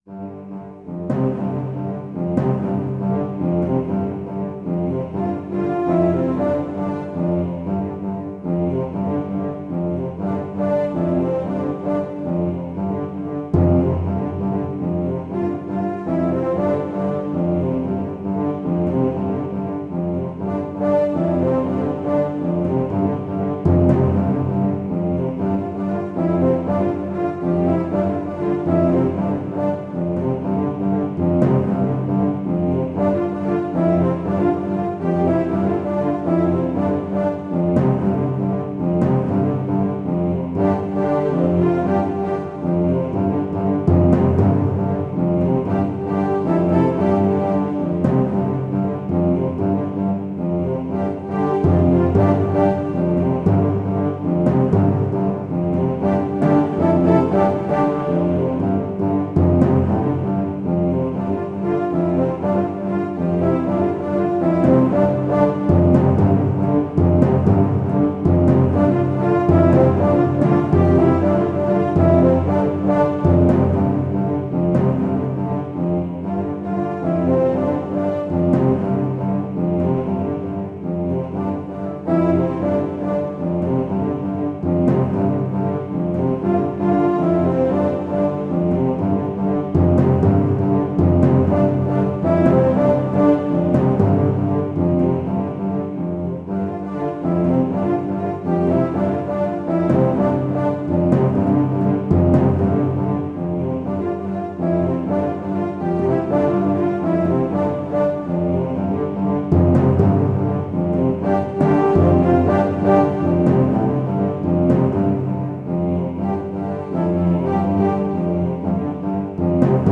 Brasses, Voices with Synths.